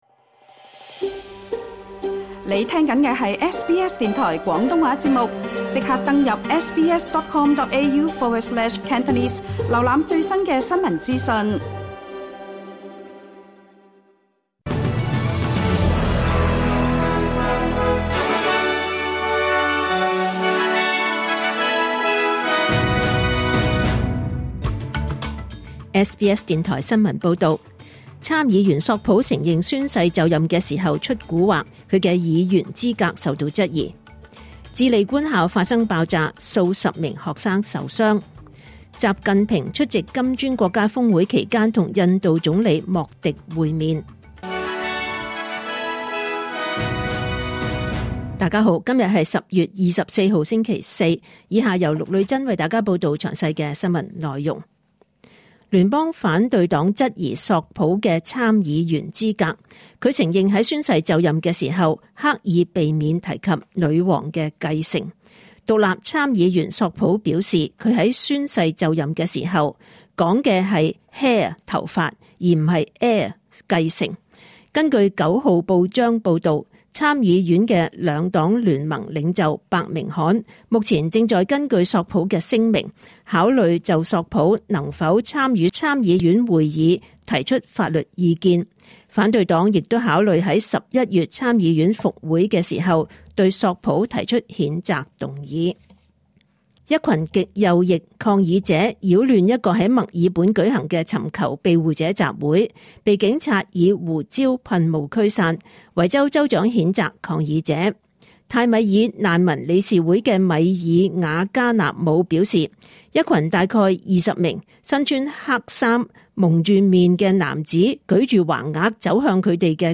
2024 年 10 月 24 日 SBS 廣東話節目詳盡早晨新聞報道。